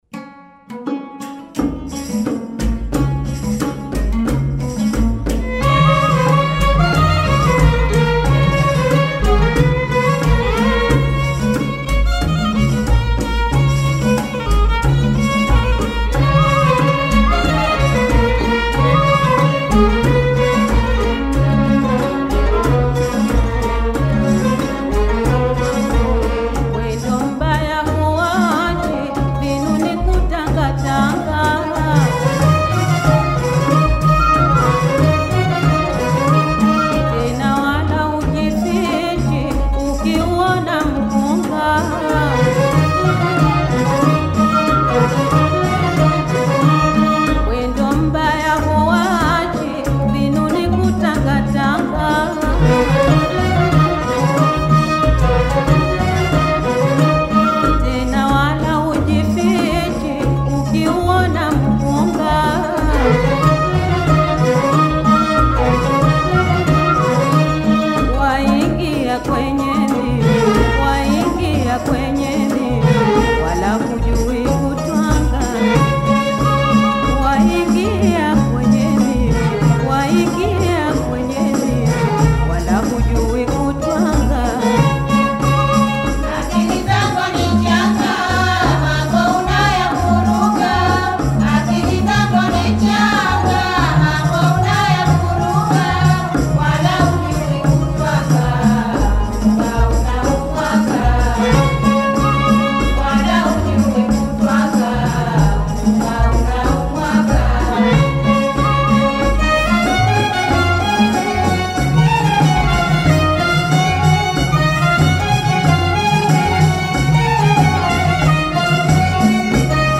taarab music